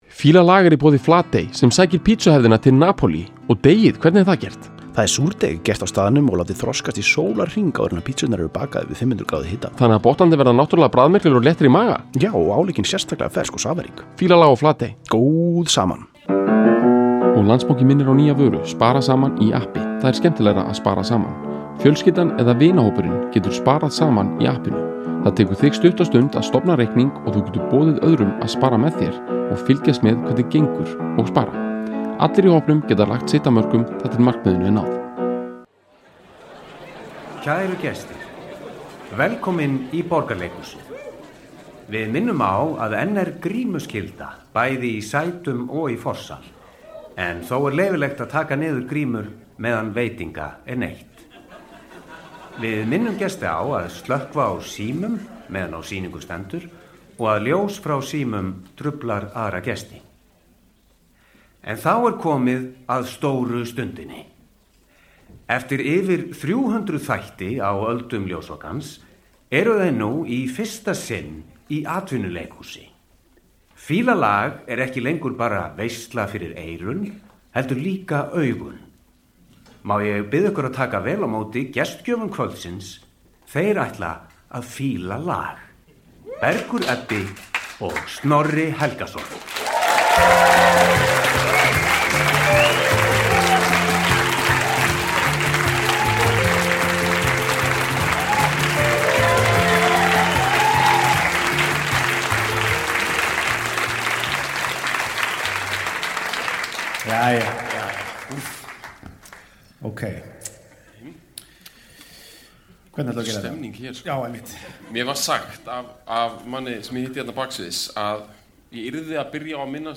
Fílun dagsins var tekin upp "live" í Borgarleikhúsinu og er því einstaklega löng og lífleg. Rætt var um ýmis myndbönd í fíluninni og eru hlekkir á þau hér.